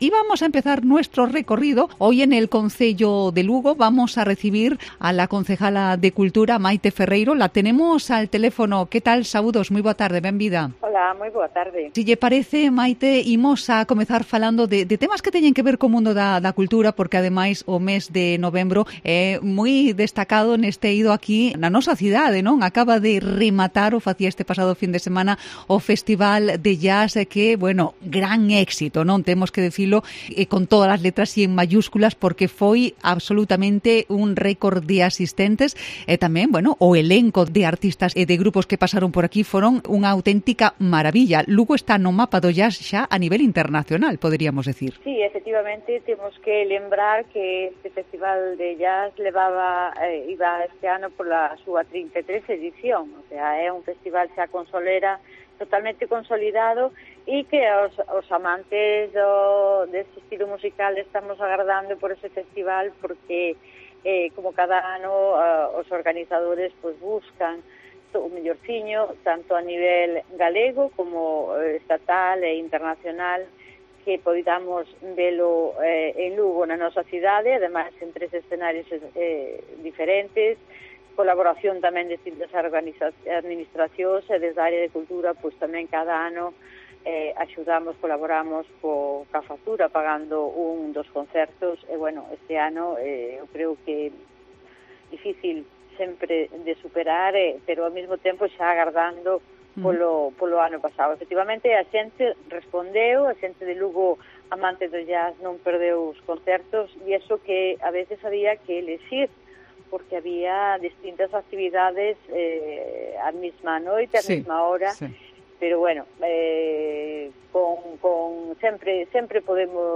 Entrevista a Maite Ferreiro, edil de Cultura e Turismo en Lugo